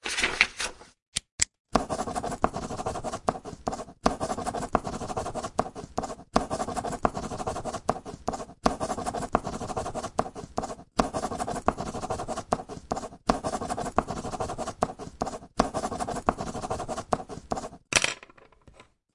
杂项：2008年10月的假唱录音 " 梳子和笔
描述：用Zoom H2录制的。
Tag: 运动 现场记录 梳子